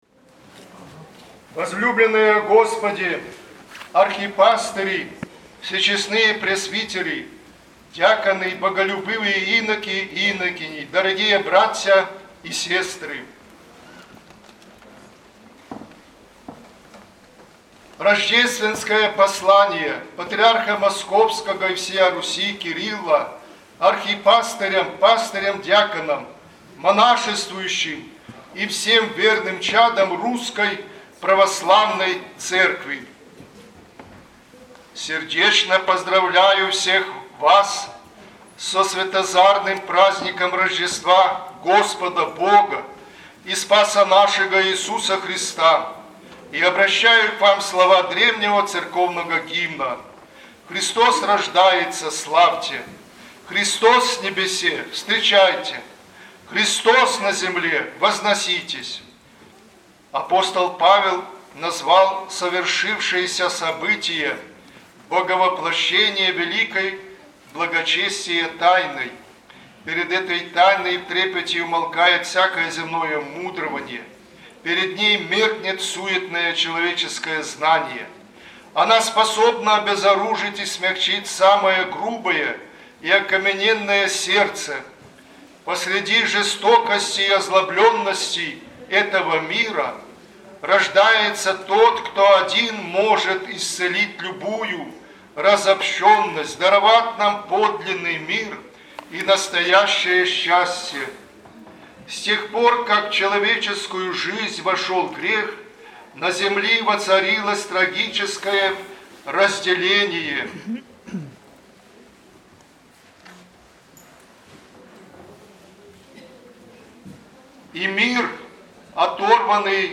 Рождественское послание Патриарха Кирилла. 07.01.2026